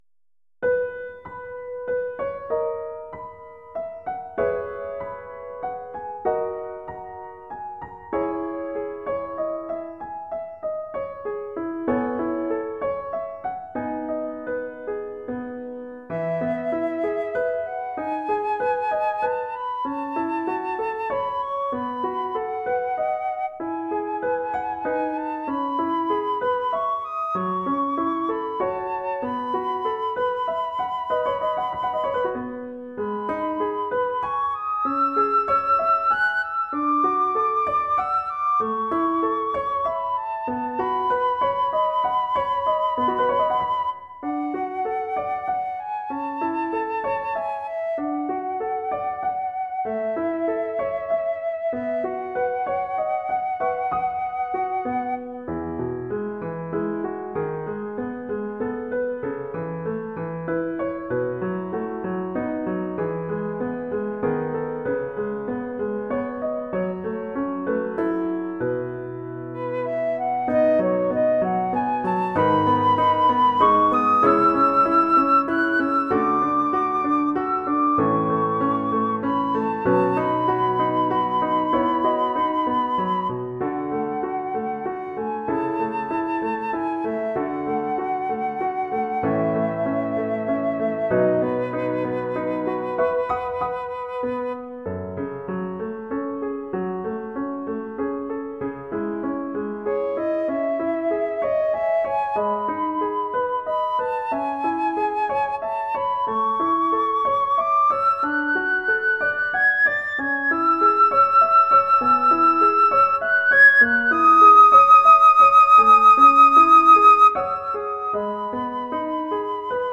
Flute & Piano